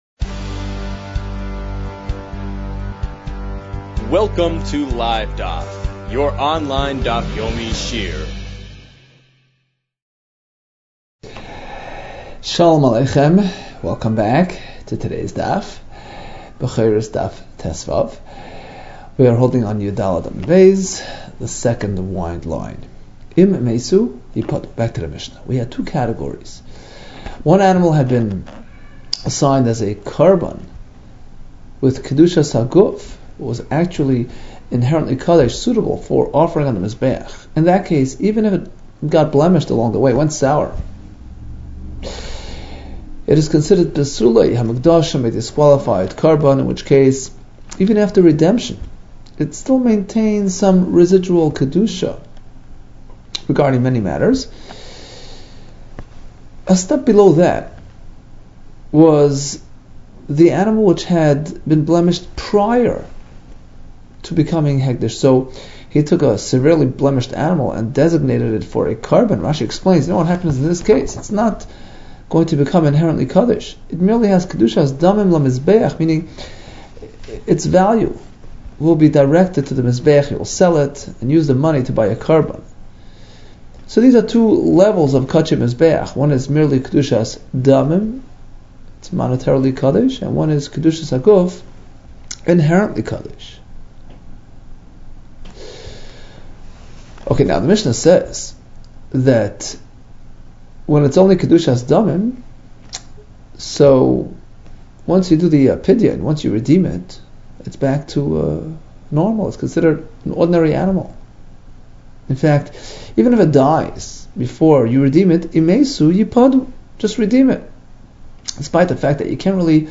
Bechoros 14 - בכורות יד | Daf Yomi Online Shiur | Livedaf